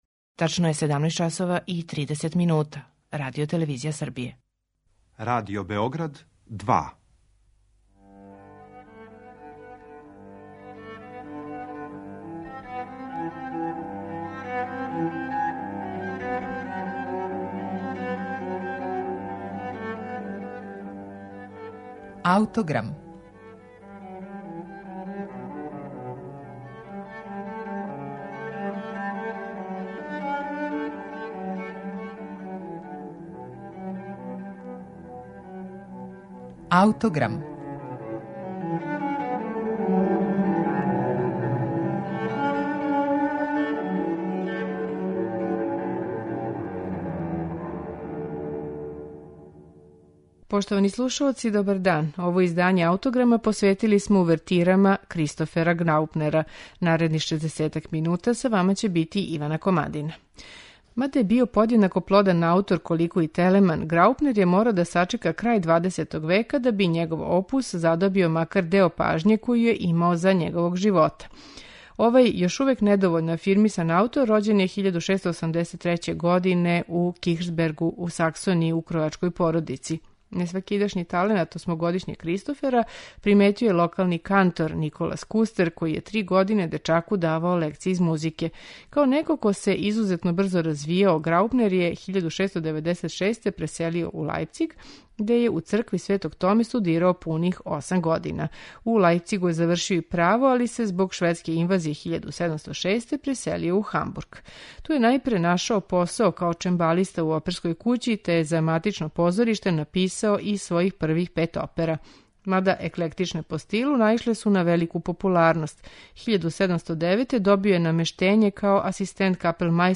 Представићемо две увертире Кристофера Граупнера